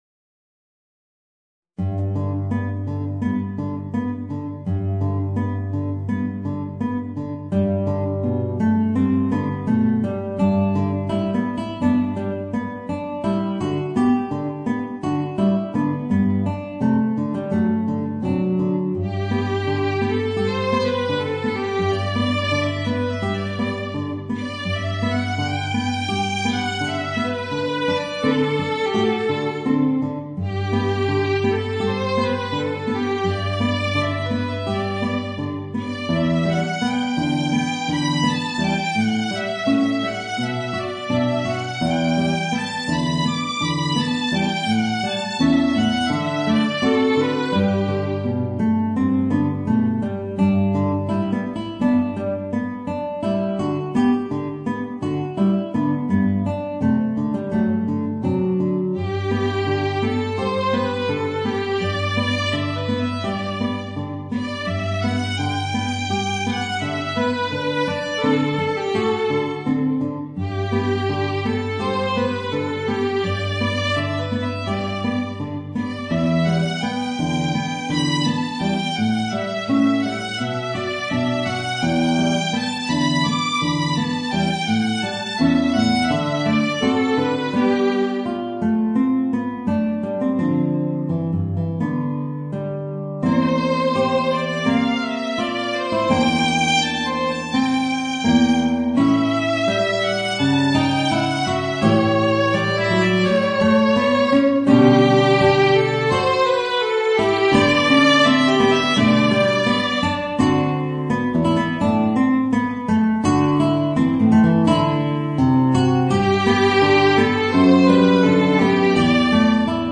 Voicing: Violin and Guitar